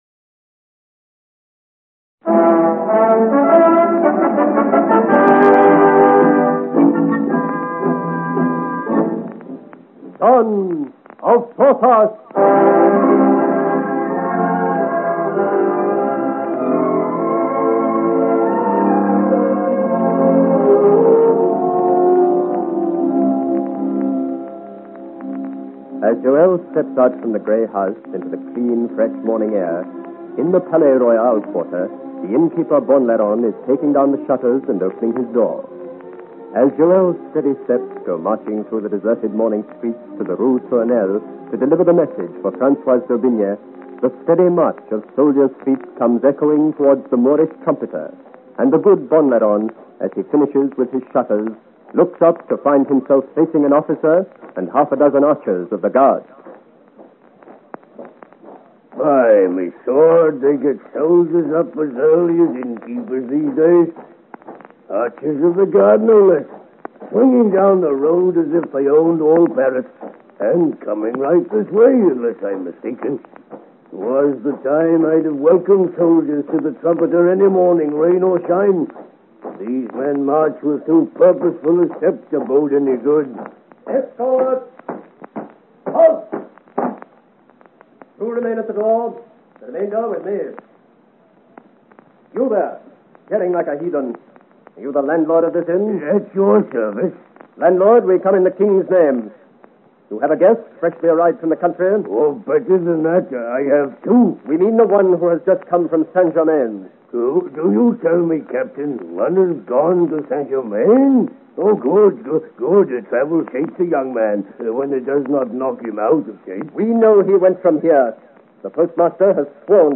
Genre: Adventure, Romance, Drama